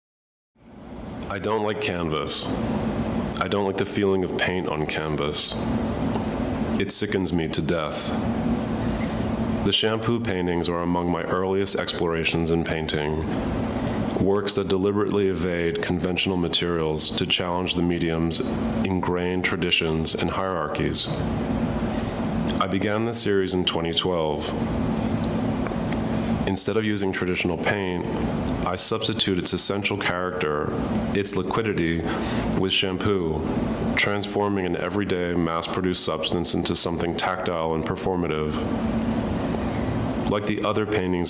But in the audio guide, Da Corte also talked about this innovative painting technique in general, and if you click on the headphones at left, you can hear his comments.